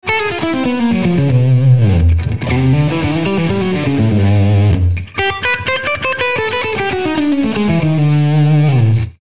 Designed to work with the latest amps as well as the old favorite tube amps, the FET Booster Amp? lets you get a hotter signal from your instrument to your amp, whether you want a clean, smooth boost in gain, or faster distortion.